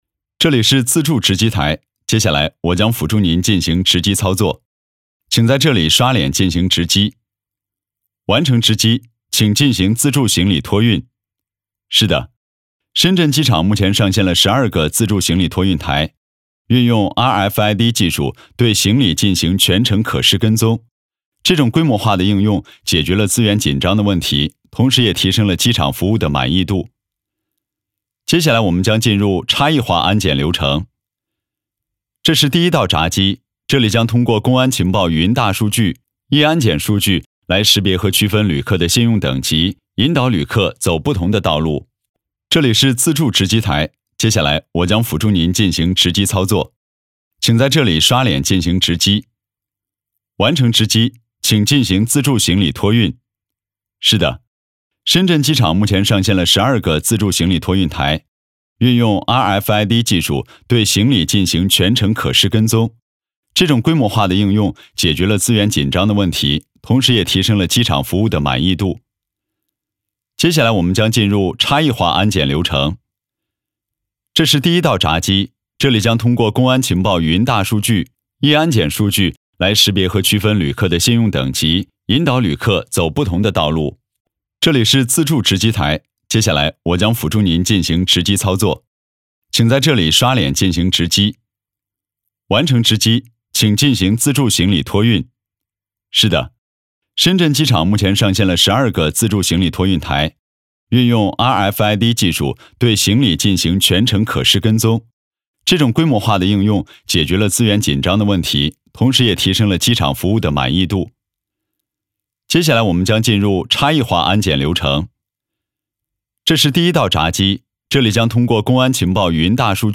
职业配音员全职配音员高性价比
• 男1 国语 男声 深圳机场播报 提示语 干音 积极向上|亲切甜美